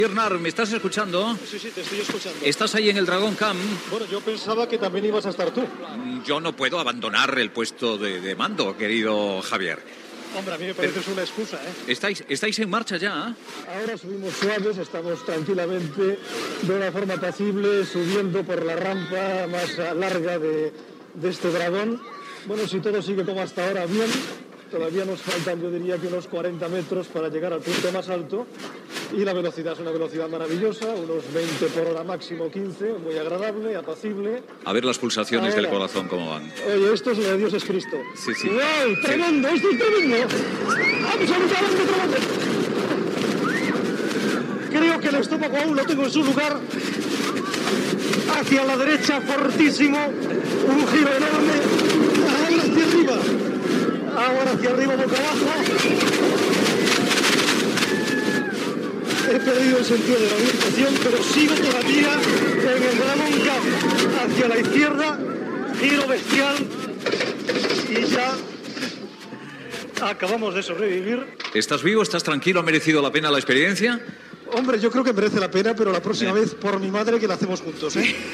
El col·laborador del programa, l'advocat Javier Nart, transmet des del Dragon Kahn de Port Aventura.
Info-entreteniment